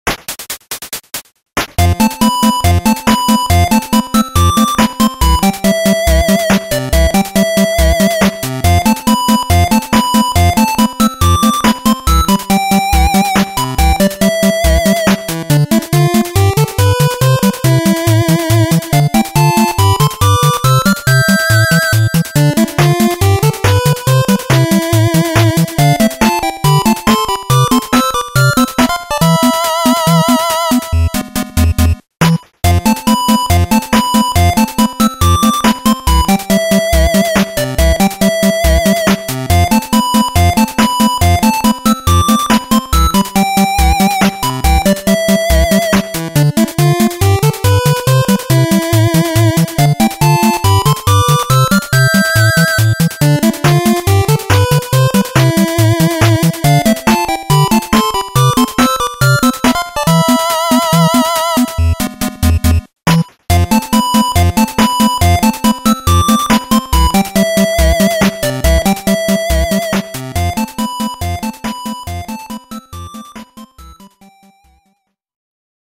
8 Bit Remix